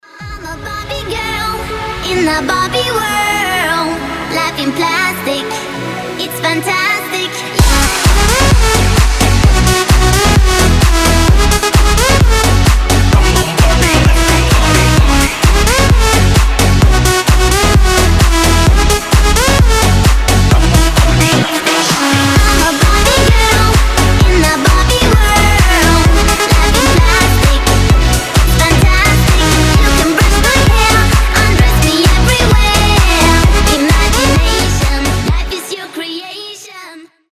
клубные , танцевальные , евродэнс , ремиксы